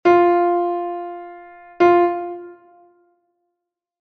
Branca con dobre puntiño igual a branca, negra e corchea ligadas; negra con dobre puntiño igual a negra, corchea e semicorchea ligadas.